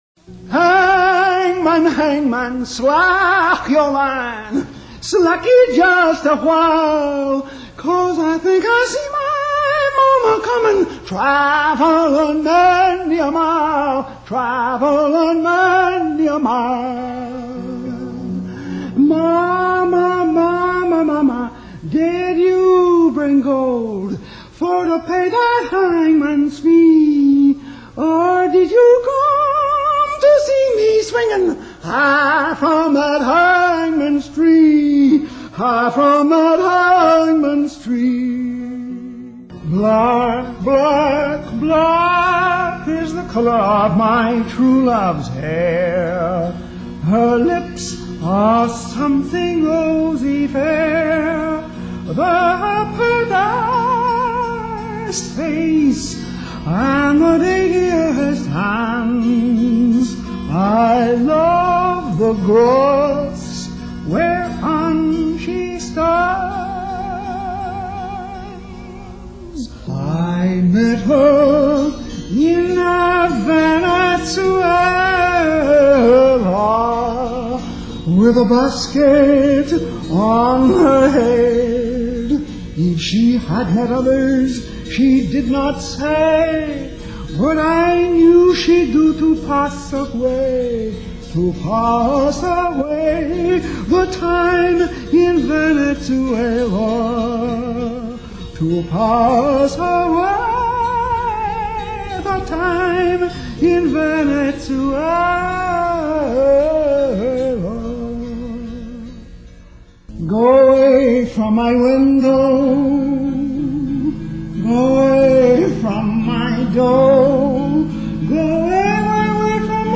This medley isn't a very good representation of his music.